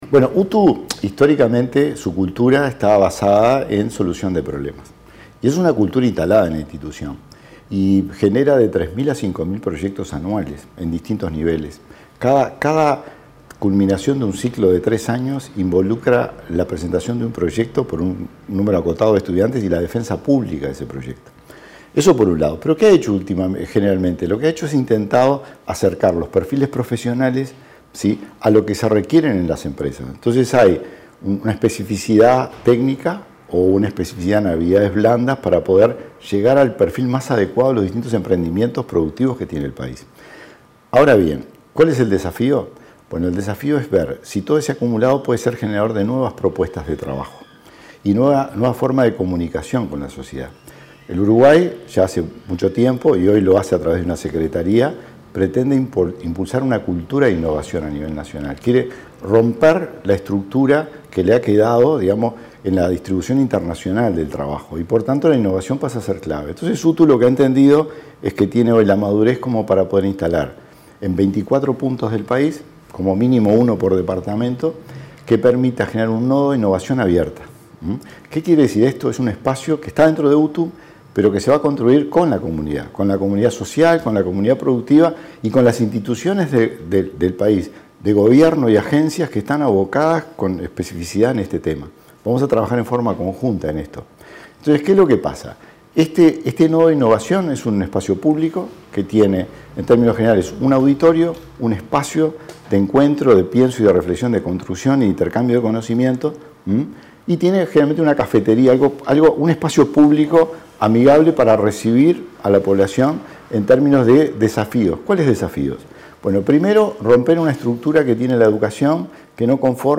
Declaraciones del subdirector de la UTU, Wilson Netto
Declaraciones del subdirector de la UTU, Wilson Netto 27/10/2025 Compartir Facebook X Copiar enlace WhatsApp LinkedIn La Dirección General de Educación Técnico Profesional - UTU lanzó la Red Nacional de Nodos de Innovación, 24 espacios territoriales destinados a construir de forma colectiva soluciones a problemas concretos y promover la cultura de la innovación. Al respecto, se expresó el subdirector de la institución, Wilson Netto.